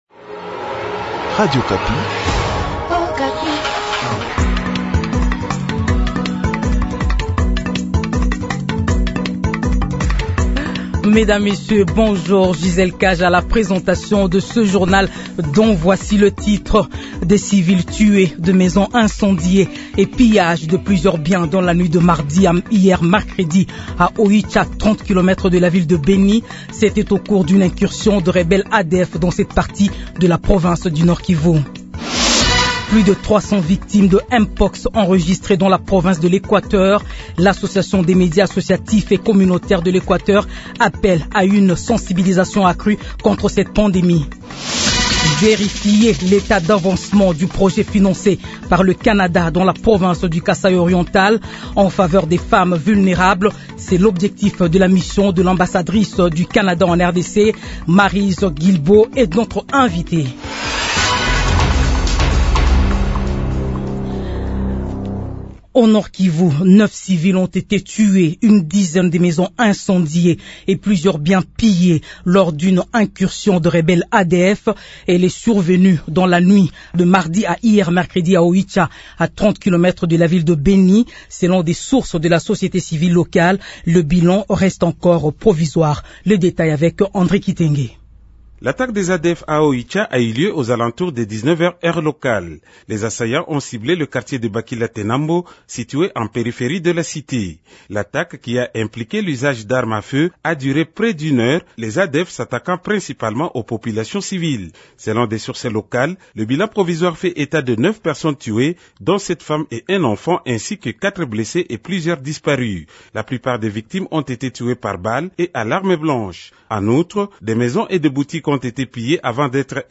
Journal français de 8 heures